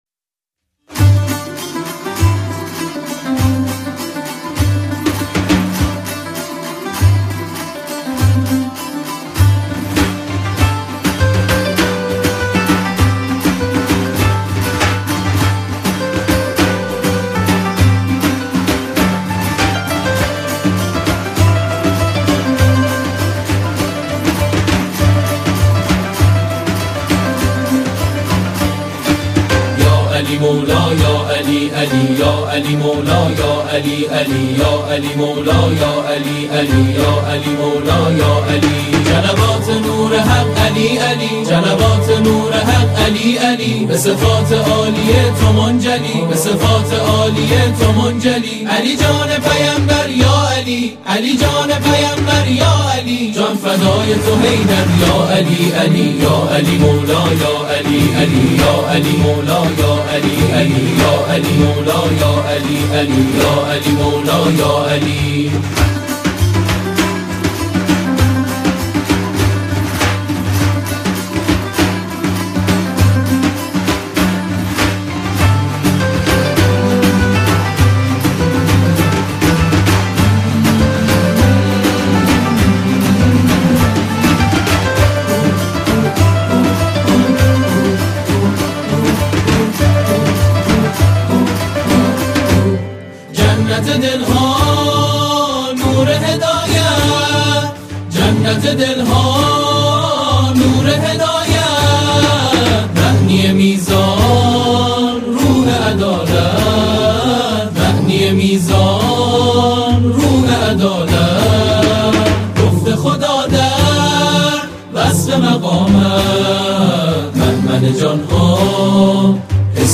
سرود
اثری شورانگیز و حماسی
قوالی
بر اساس موسیقی سنتی هندوستان و پاکستان